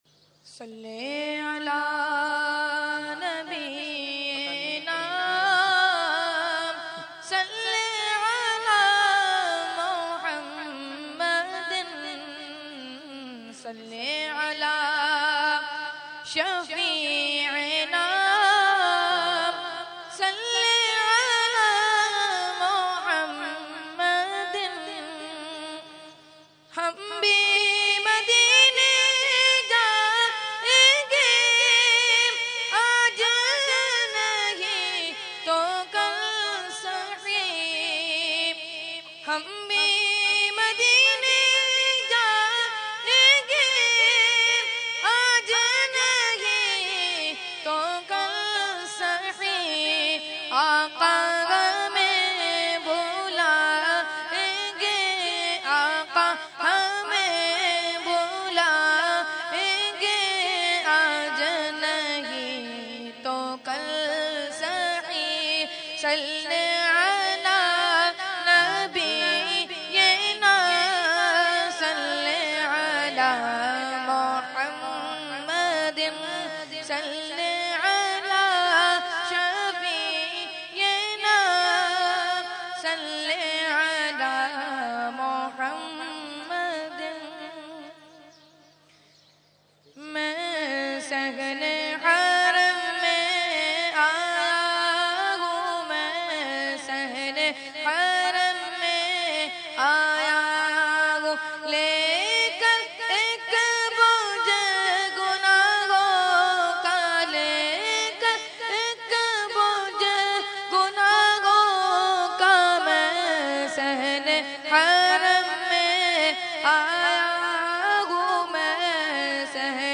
Category : Hamd | Language : UrduEvent : Urs Qutbe Rabbani 2015